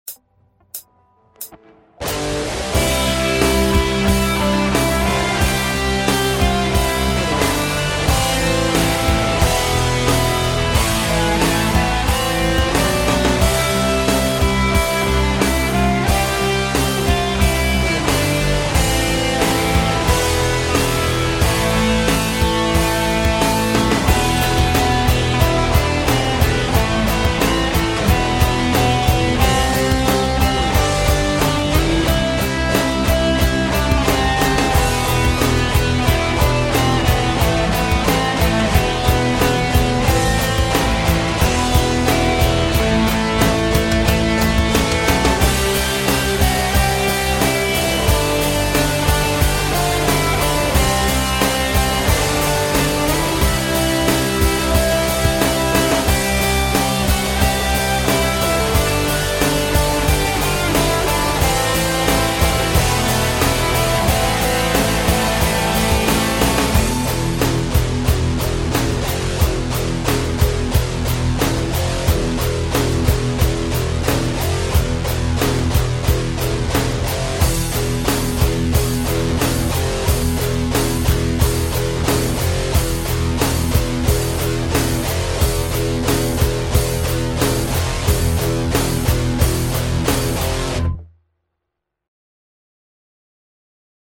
Gimn_Ukrainy___Metal_obrobka_www_hotplayer_.mp3